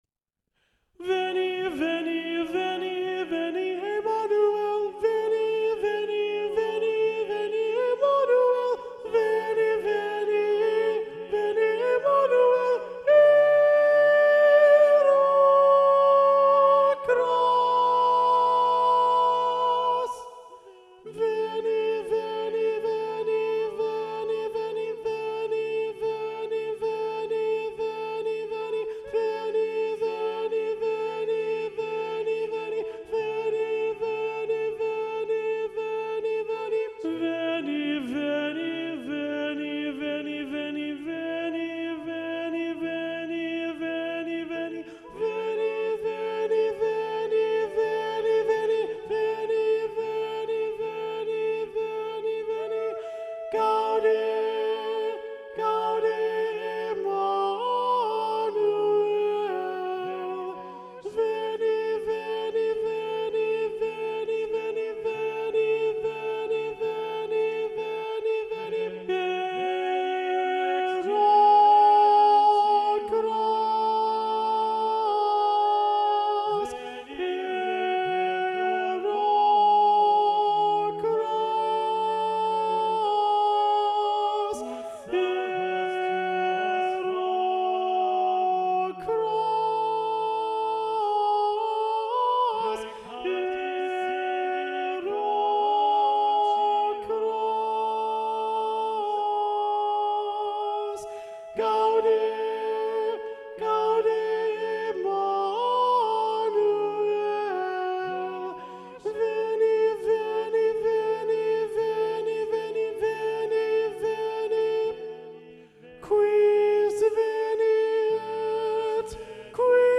Veni, Veni Emmanuel SATB – Soprano 2 Predominant – Micahel John Trotta